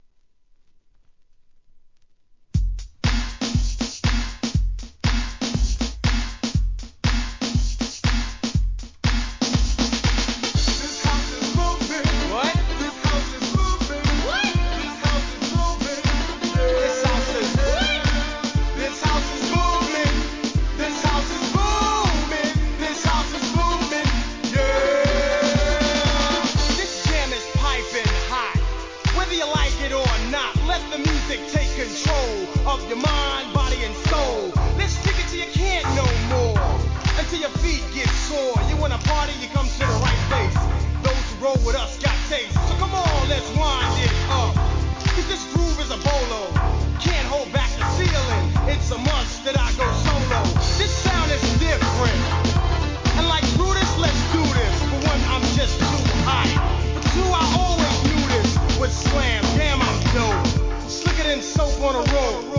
HIP HOUSE!!